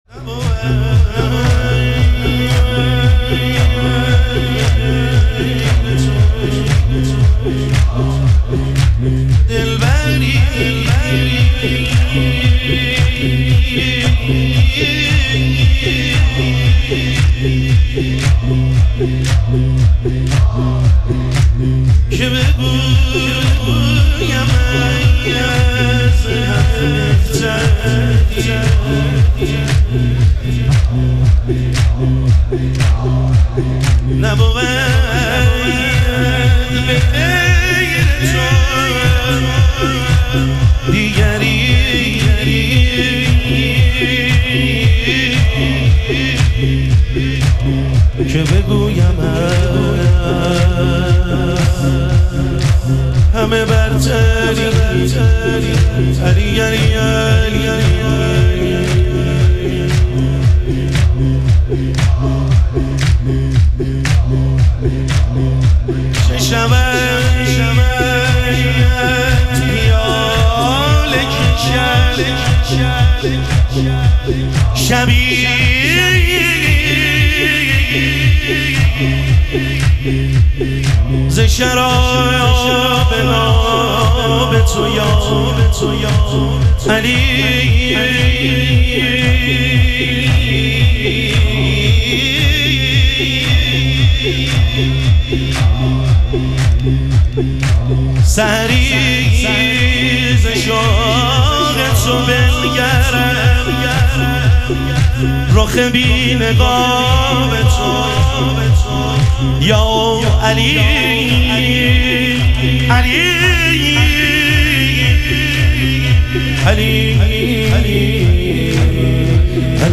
اربعین امیرالمومنین علیه السلام - تک